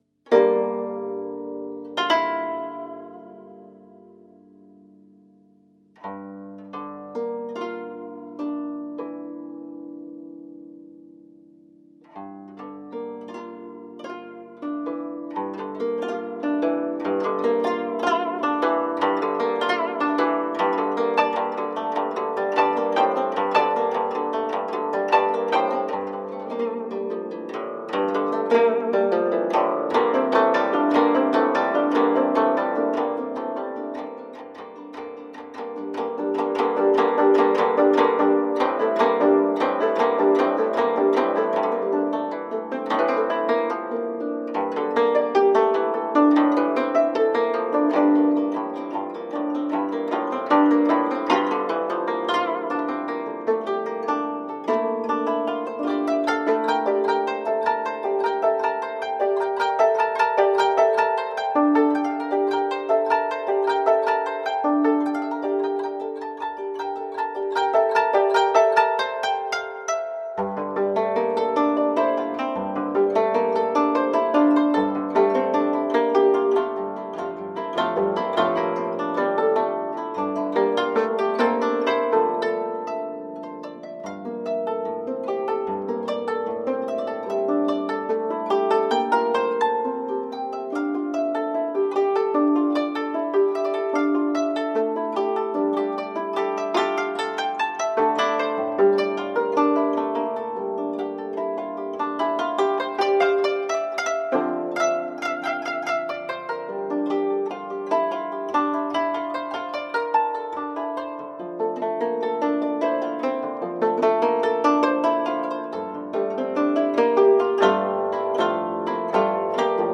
Melodic and mixed world tunes on japanese koto.
Tagged as: World, Other, Hammered Dulcimer, World Influenced